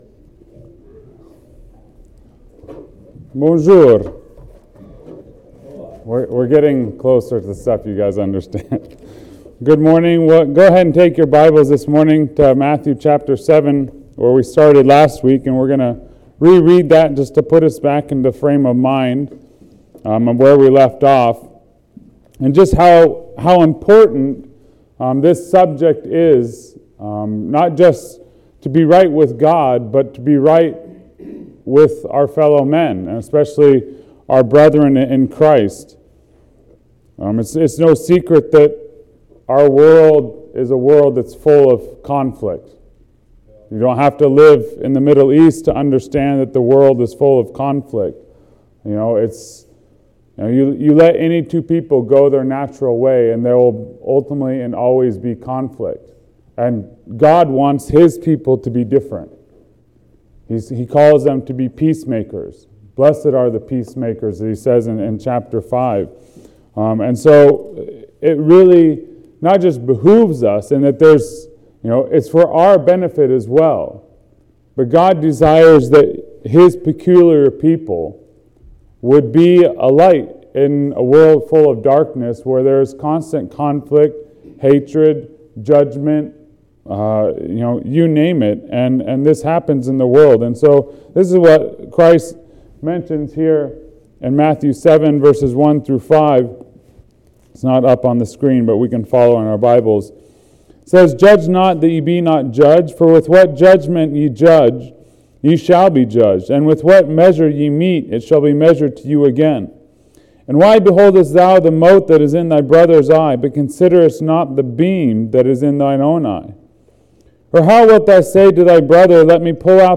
Sermons | Plack Road Baptist Church